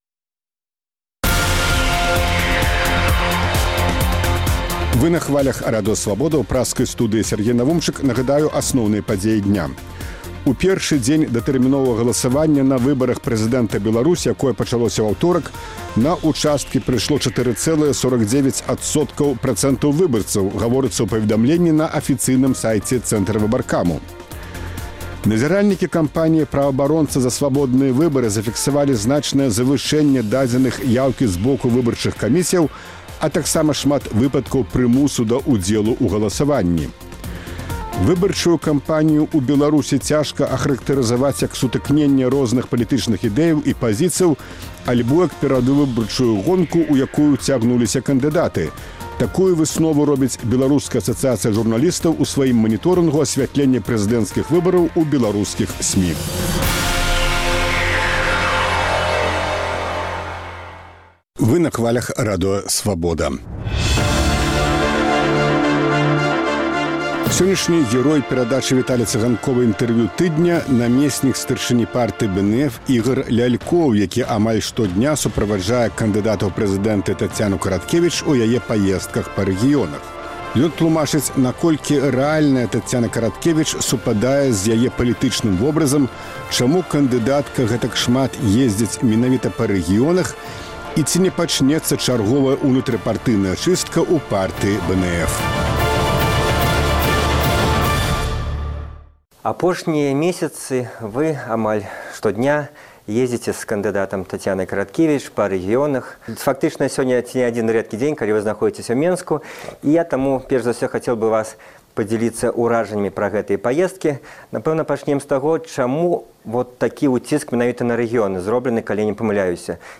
Інтэрвію тыдня